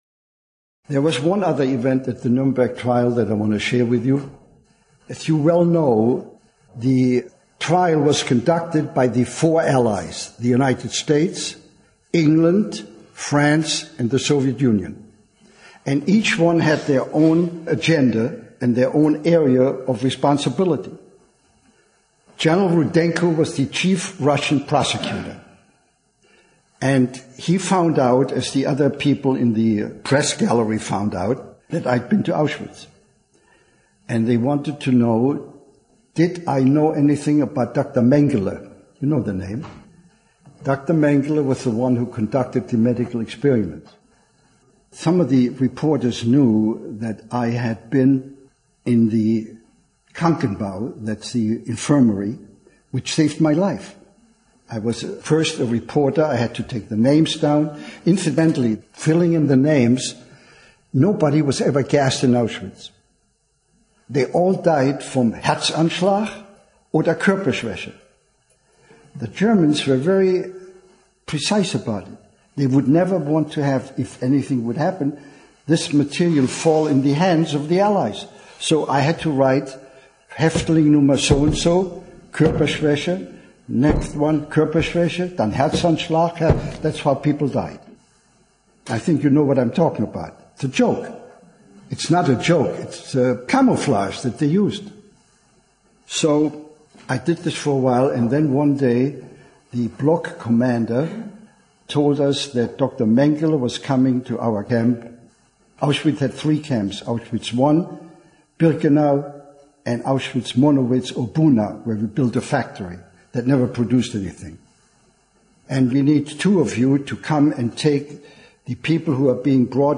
Aufgezeichnet am 22. November 2005 im Jüdischen Museum Berlin